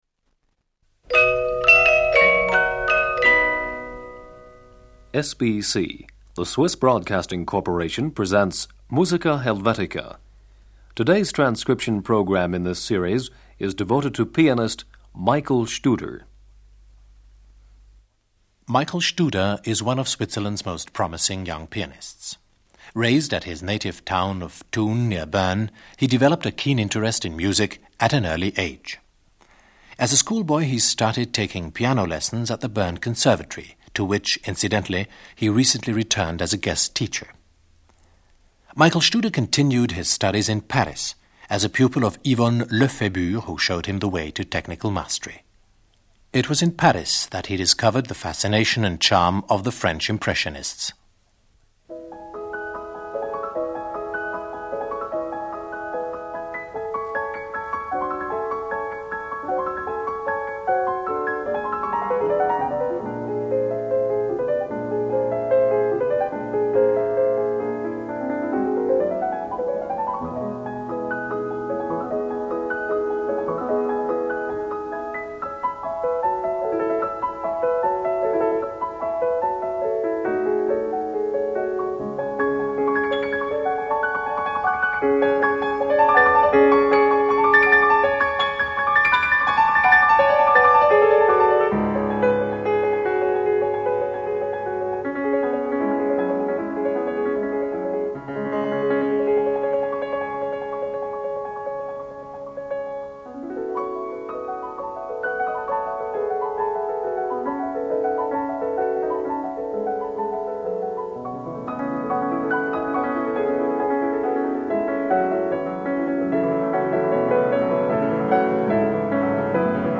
piano
Tibor Chamber Orchestra. Tibor Varga, conductor.